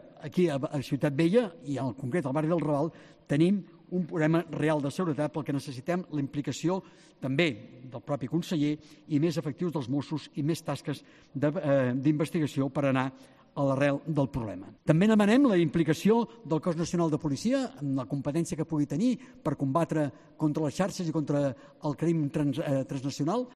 Declaración: Albert Batllé, teniente de alcalde de Prevención y Seguridad, en el Ayuntamiento de Barcelona